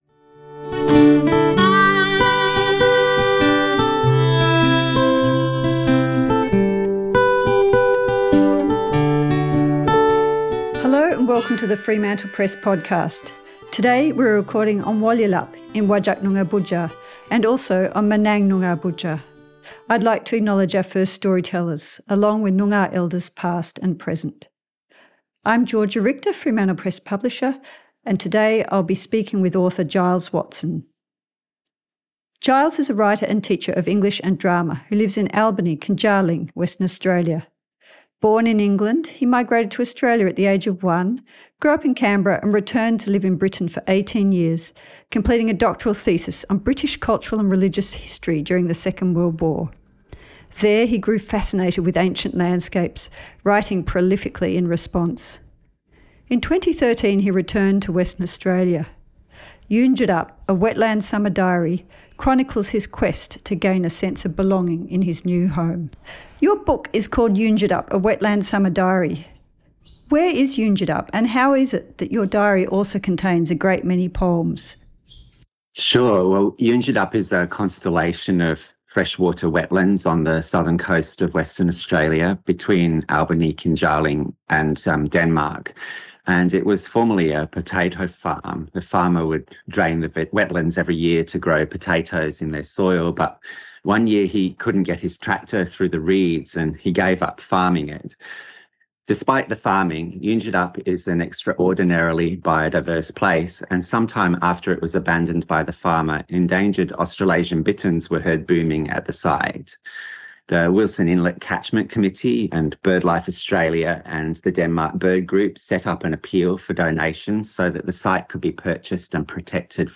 Poetry, chronic illness, and the living wetlands of Eungedup: A conversation